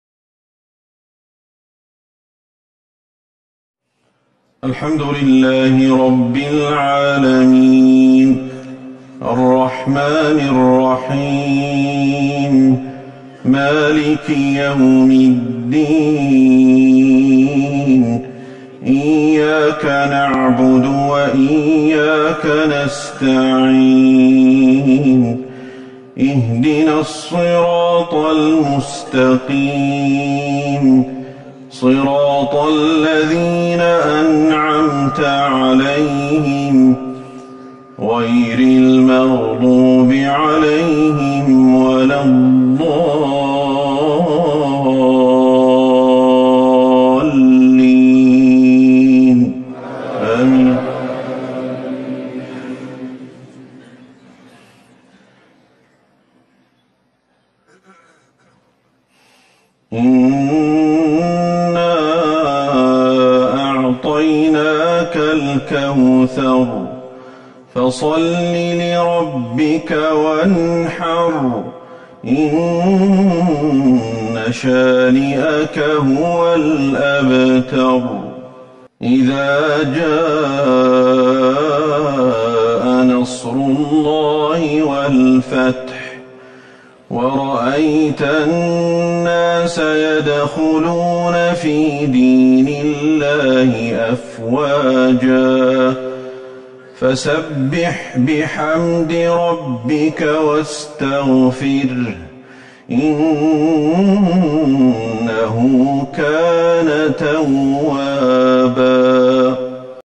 صلاة المغرب 14 جمادى الاولى 1441 من سورة الكوثر والنصر | Maghrib Prayer 2-1-2020 from Surat al-Kauthar and al-Nasr > 1441 هـ > الفروض